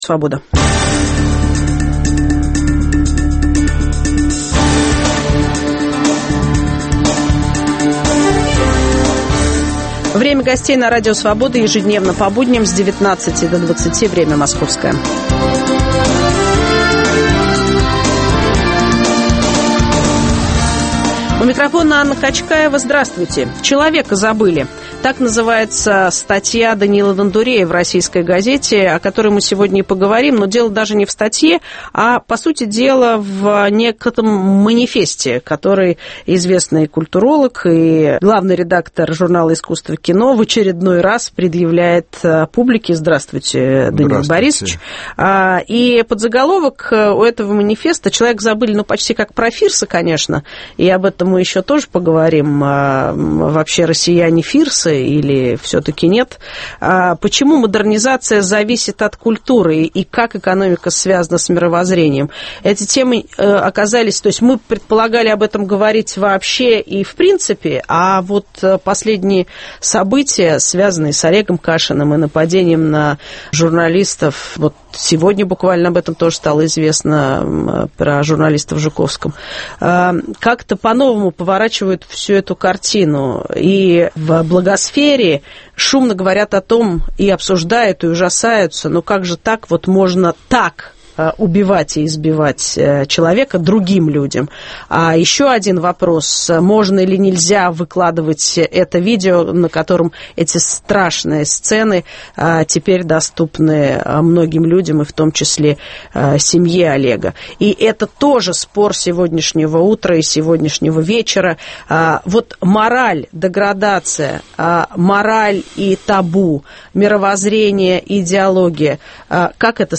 Почему модернизация зависит от культуры и мировоззренческих активов? Зачем стране нужны "заказчики порывов"? В студии - социолог культуры, главный редактор журнала "Искусство кино" Даниил Дондурей.